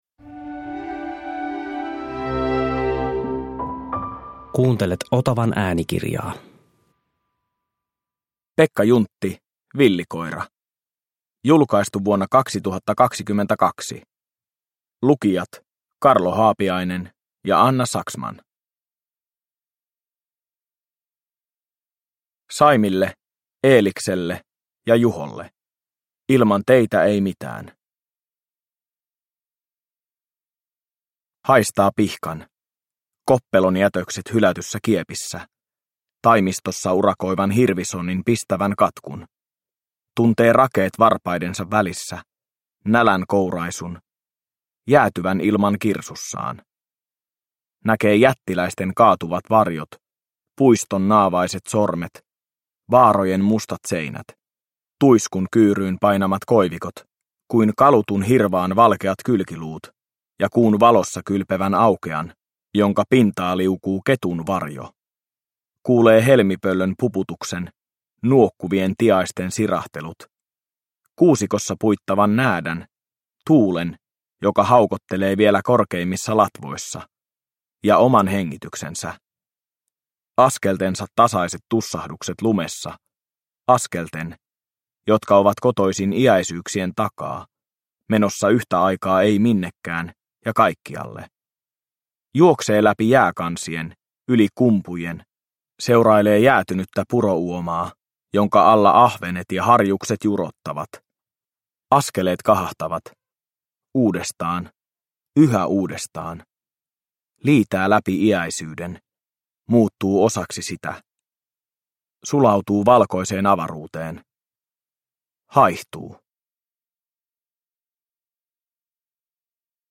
Villikoira – Ljudbok